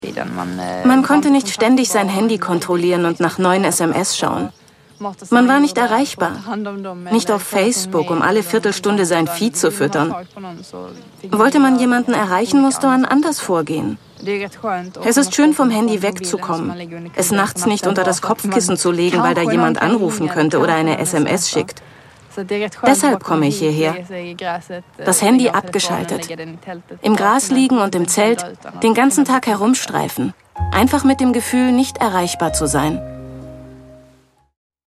deutsche Sprecherin,Hörspiel,Computerspiele,Werbung,Voice over,Imagefilm,Hörbuch variabel von rauchig dunkel bis spritzig frisch, sexy.
Sprechprobe: Sonstiges (Muttersprache):